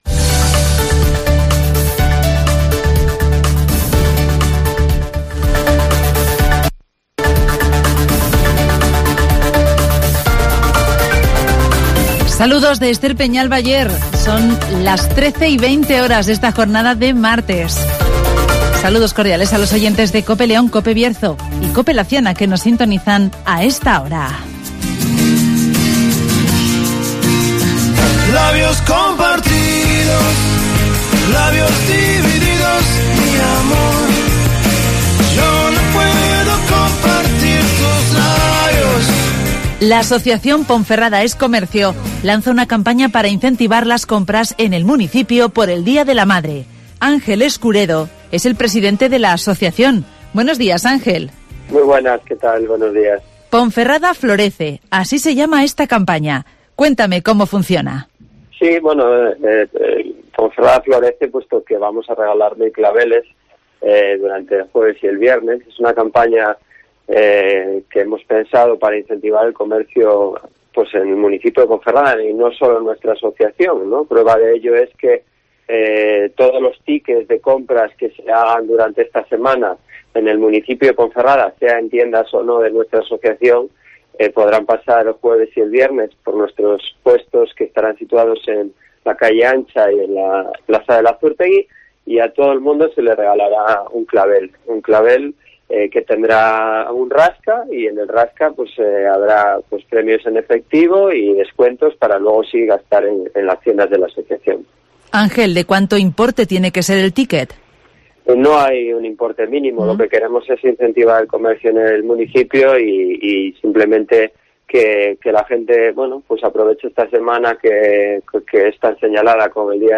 Ponferrada Es Comercio pone en marcha por el Día de la Madre la campaña Ponferrada Florece (Entrevista